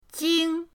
jing1.mp3